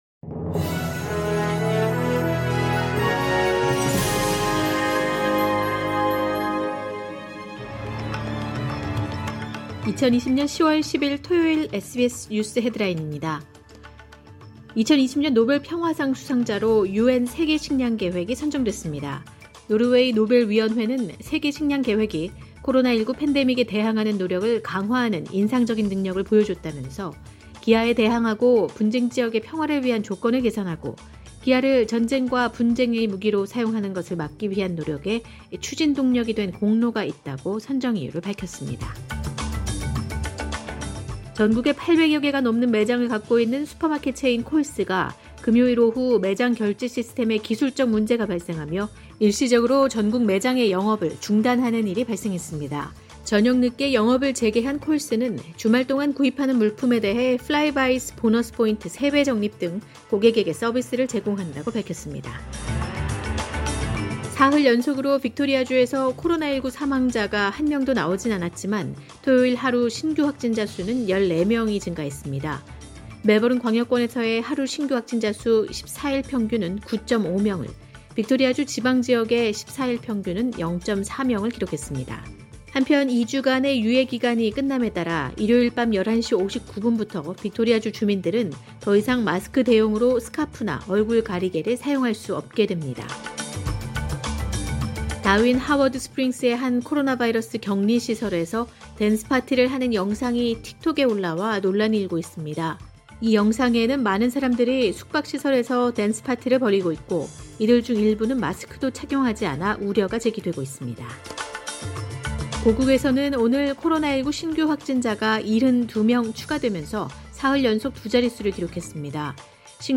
2020년 10월 10일 토요일 오전의 SBS 뉴스 헤드라인입니다.